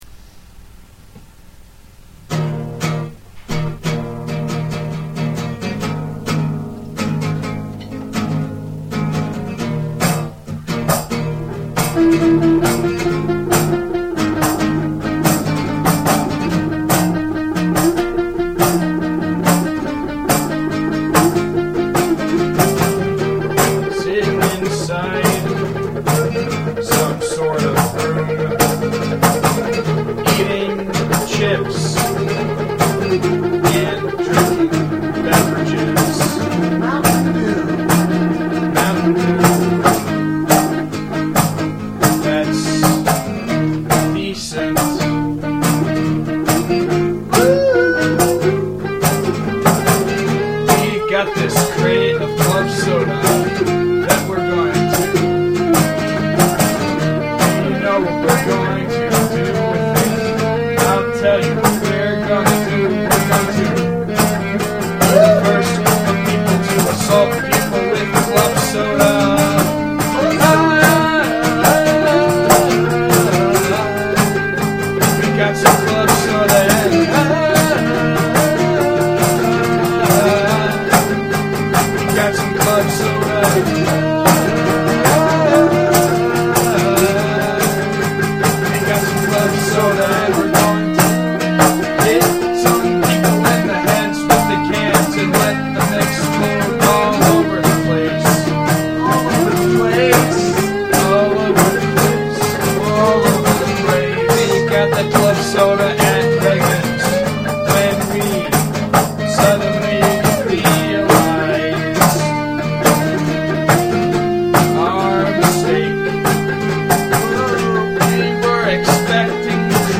totally improvised!